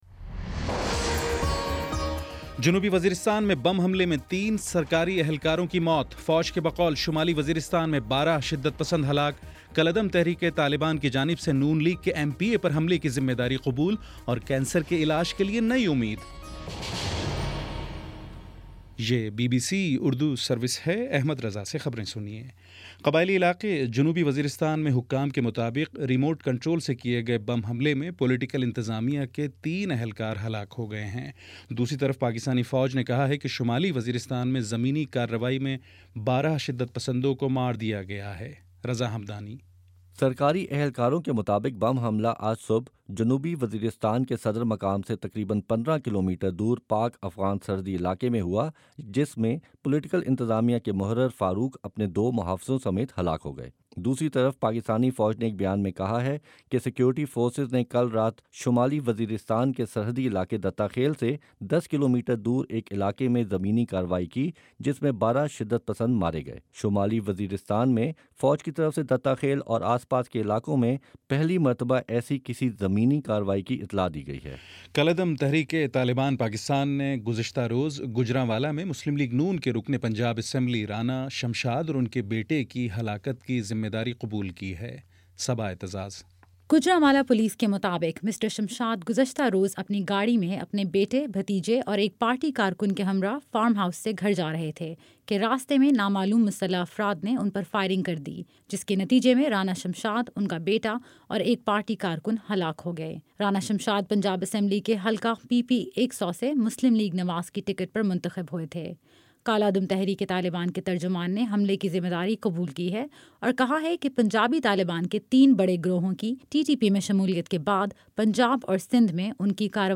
جون 1: شام پانچ بجے کا نیوز بُلیٹن